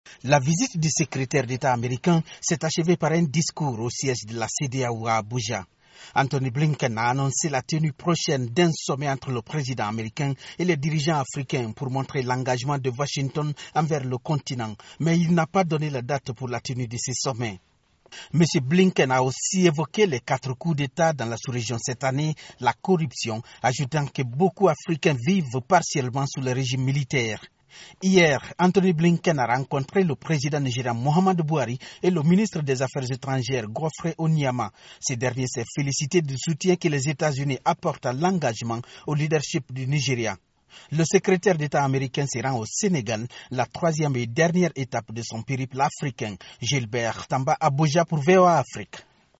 à Abuja